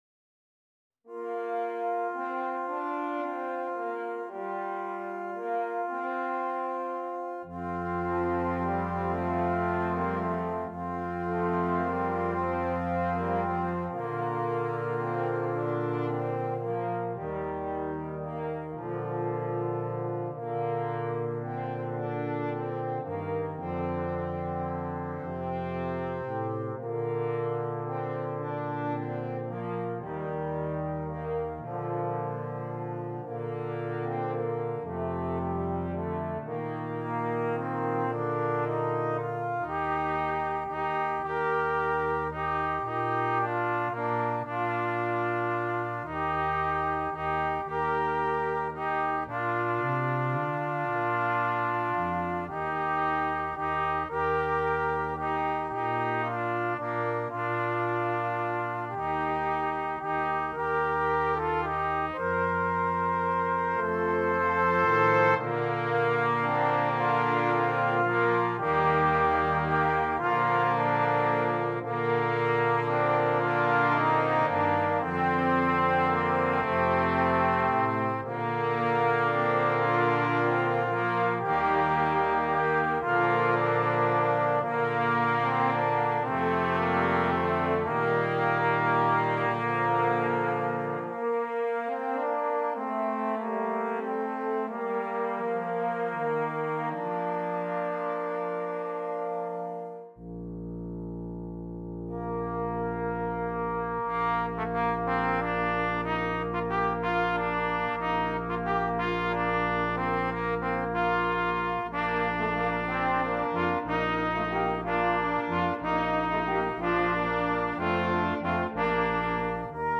Brass Quintet
solemn, chorale-like movement